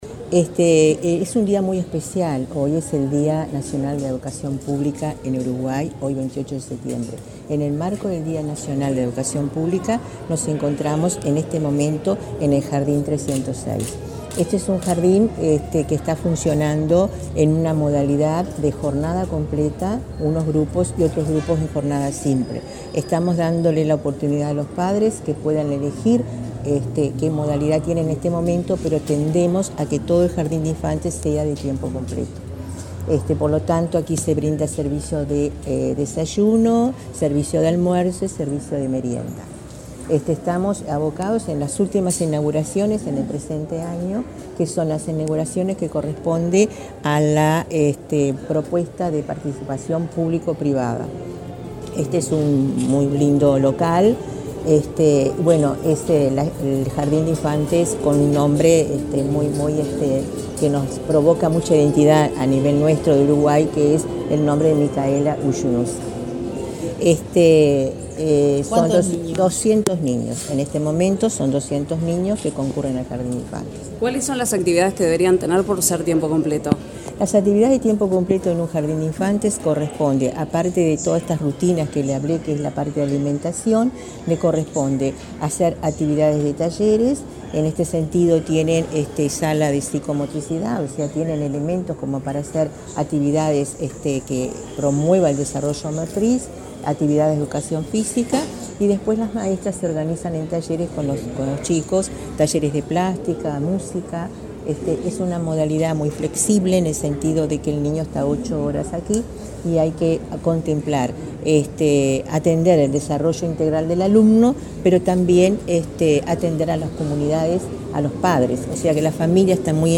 Declaraciones de la directora general de Educación Inicial y Primaria a la prensa
La directora general de Educación Inicial y Primaria, Olga de las Heras, dialogó con la prensa acerca de la inauguración de dos jardines de infantes,